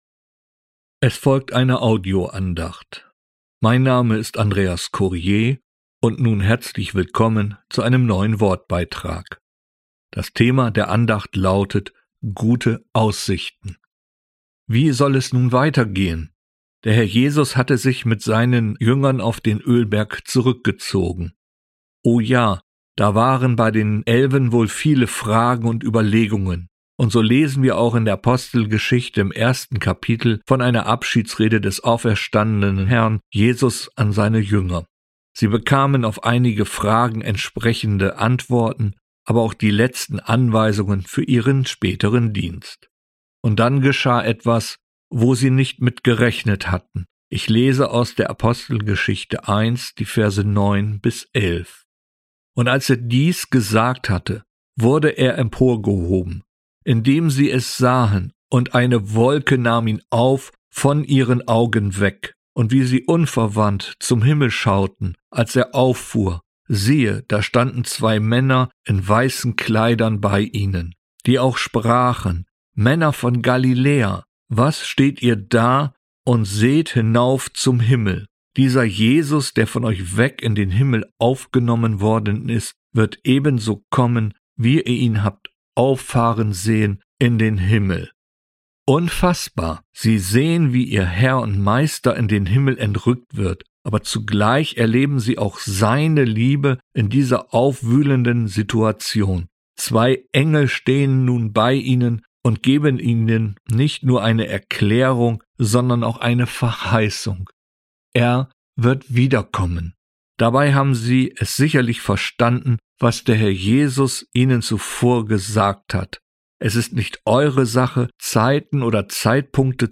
Gute Aussichten - eine Andacht